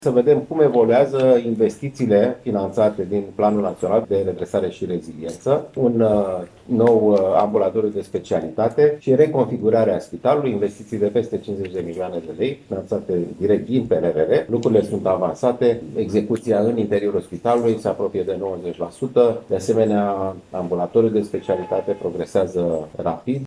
Cu acest prilej, Alexandru Rafila a a declarta că la unitatea medicală se desfășoară un program, susținut prin PNRR, care presupune reconfigurarea spitalului și reamenajarea Ambulatoriului.